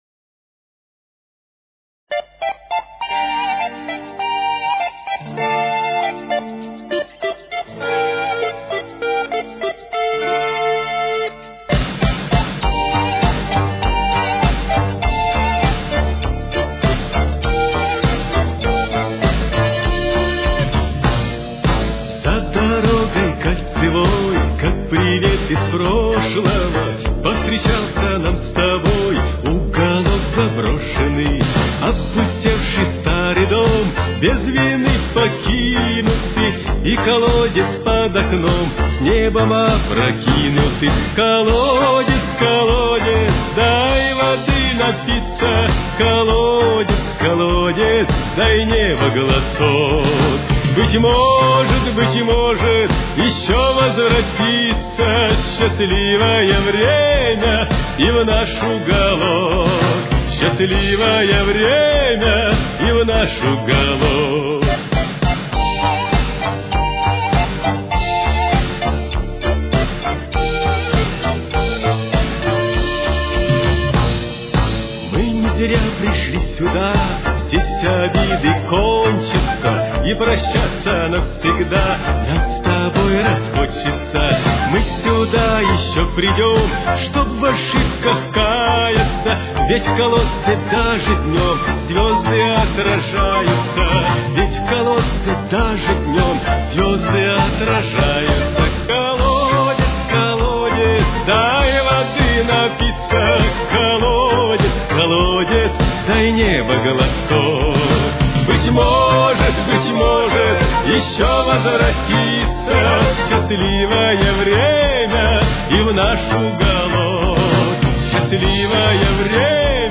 Темп: 102.